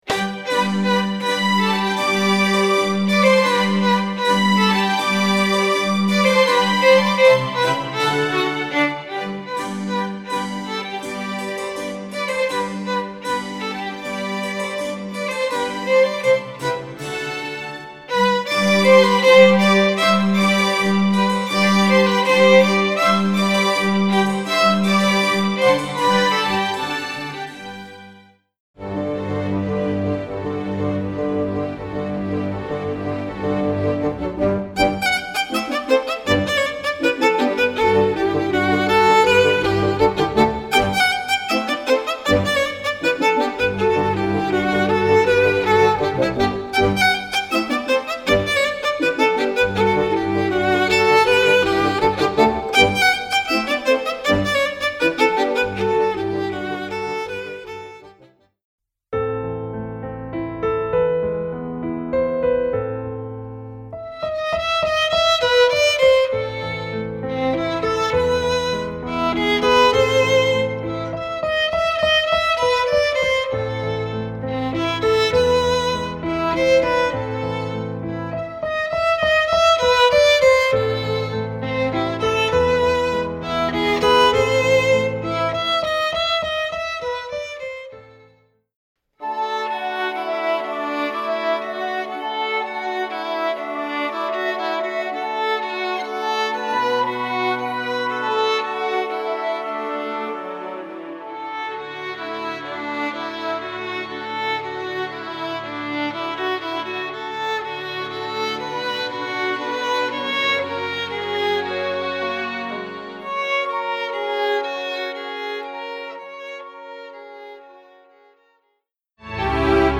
Voicing: Violin/CD